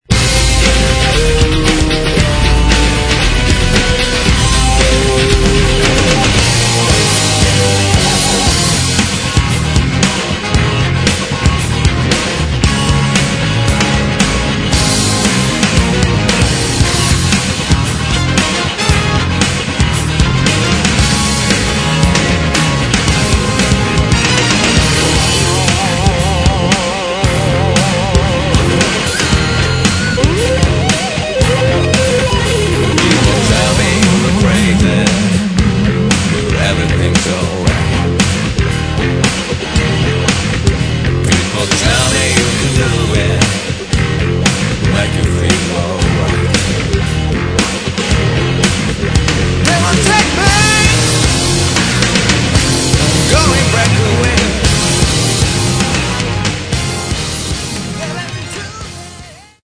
Metal
гитара, все инструменты, вокал